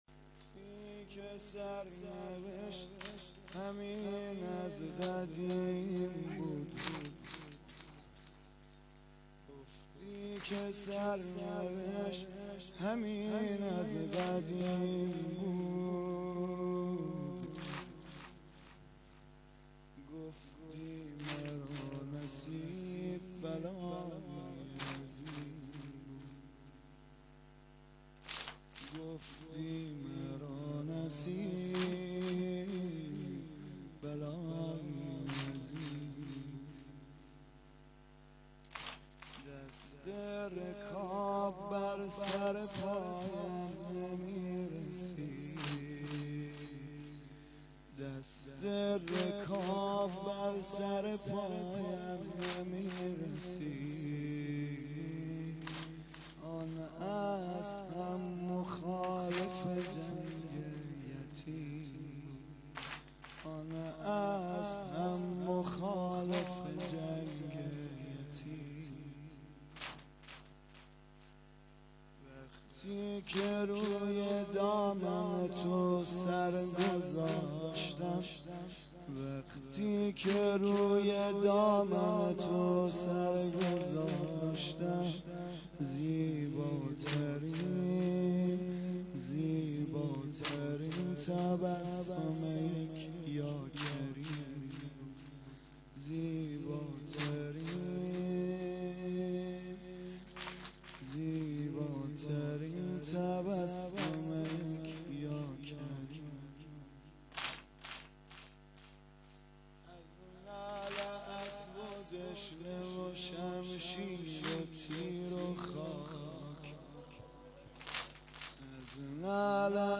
واحد شب ششم محرم1391